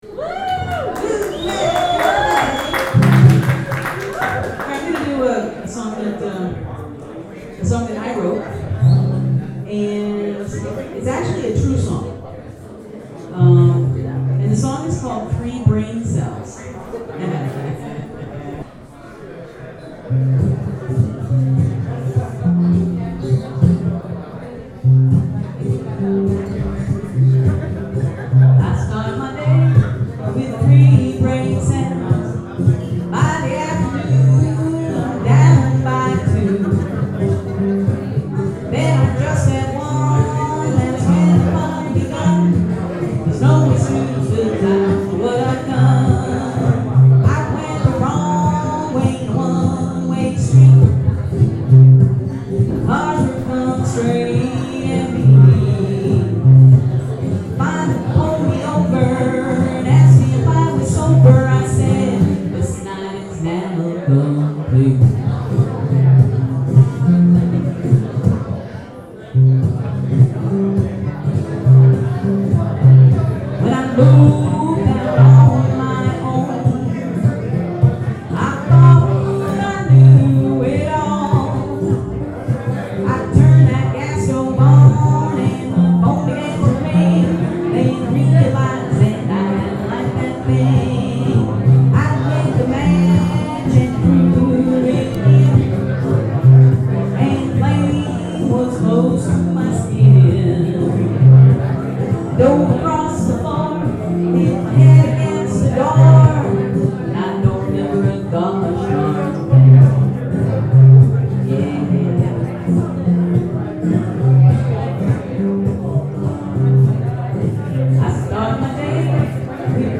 Forty-minute radio show of live performances from Friday March 2